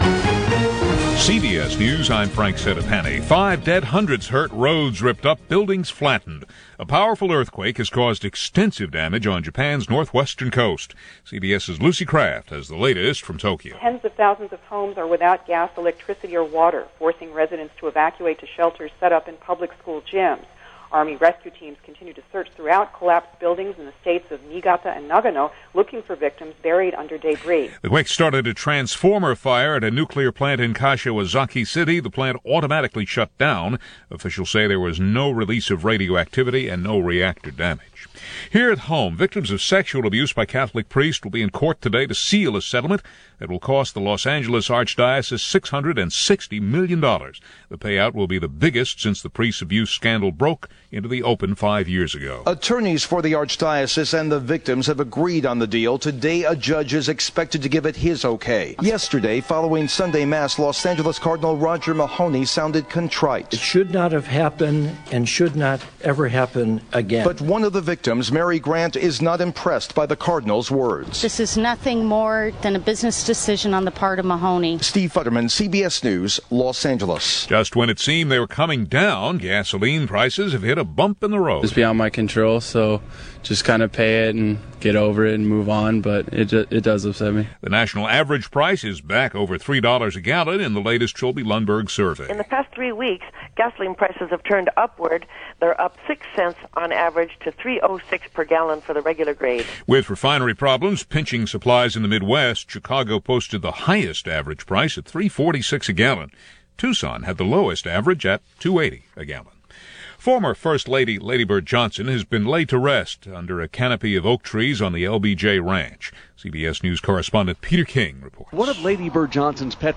And that’s some of what went on, this July 16, 2007 – a scant 18 years ago, by way of CBS Radio’s Hourly News.